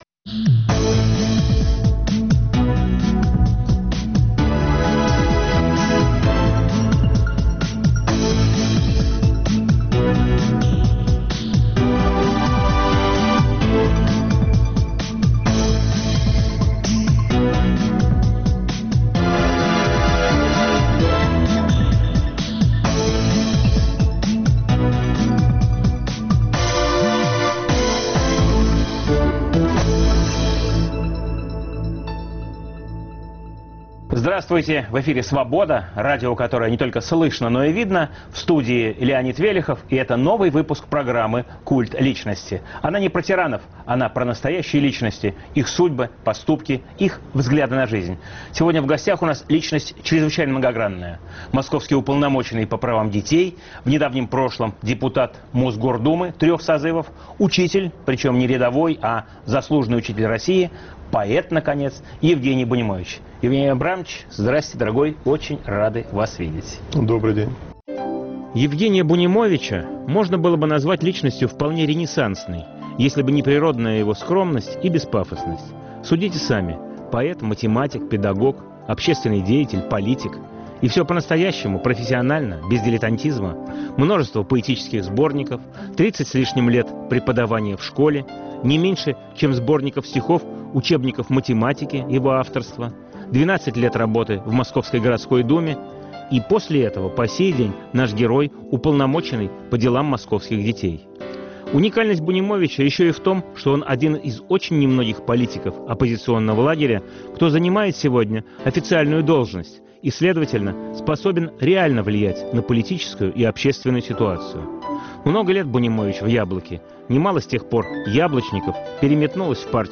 Новый выпуск программы о настоящих личностях, их судьбах, поступках и взглядах на жизнь. В студии поэт и общественный деятель Евгений Бунимович. Эфир в субботу 25 июня в 18 часов 05 минут Ведущий - Леонид Велехов.